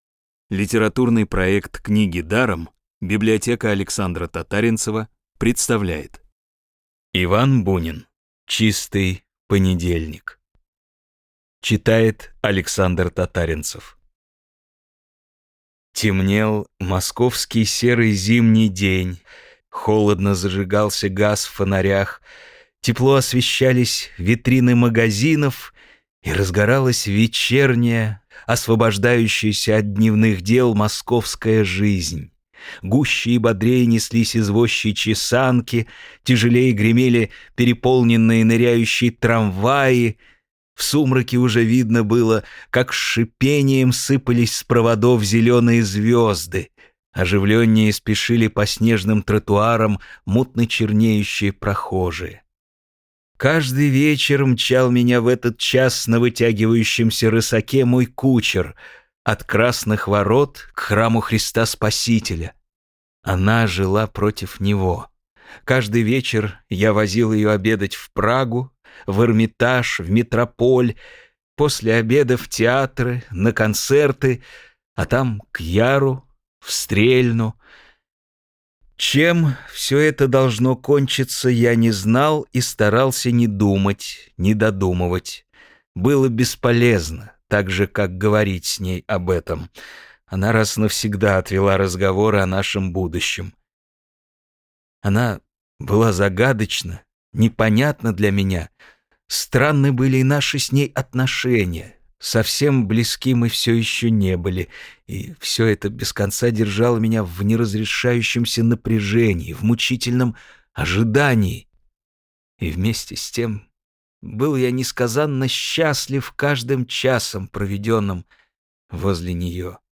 Бесплатная аудиокнига «Чистый понедельник» от Рексквер.
Классическую литературу в озвучке «Рексквер» легко слушать благодаря профессиональной актерской игре и качественному звуку.